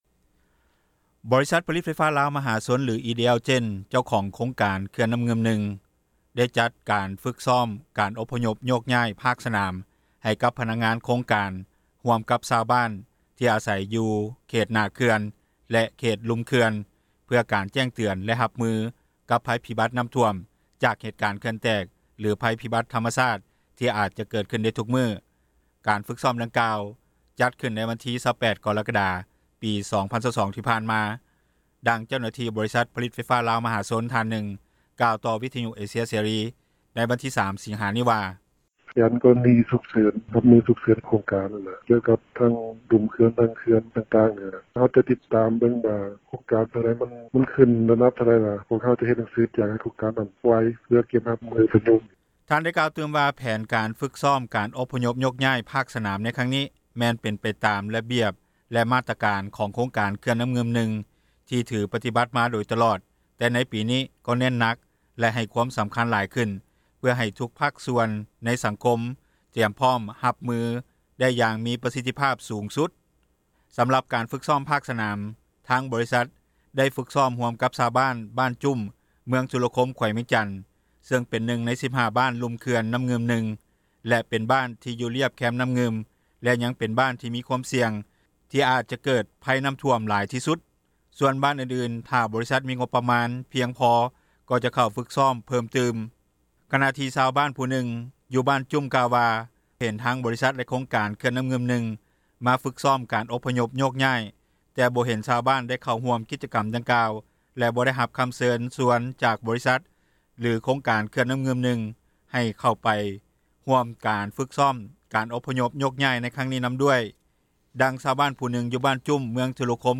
ດັ່ງເຈົ້າໜ້າທີ່ບໍຣິສັດ ຜລິຕໄຟຟ້າລາວ ມະຫາຊົນ ທ່ານນຶ່ງໄດ້ກ່າວຕໍ່ ວິທຍຸເອເຊັຽເສຣີ ໃນວັນມີ 03 ສິງຫານີ້ວ່າ:
ດັ່ງຊາວບ້ານຜູ້ນຶ່ງ ຢູ່ບ້ານຈຸ້ມ, ເມືອງທຸລະຄົມ, ແຂວງວຽງຈັນ ກ່າວຕໍ່ວິທຍຸເອເຊັຽເສຣີ ໃນວັນທີ 03 ສິງຫາ ນີ້ວ່າ: